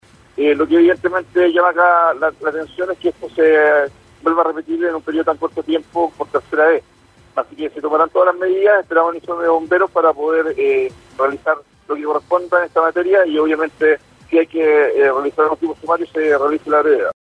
El gobernador de la provincia de Concepción, Robert Contreras, dijo que esto llama la atención, ya que son dos hechos similares en un corto período de tiempo.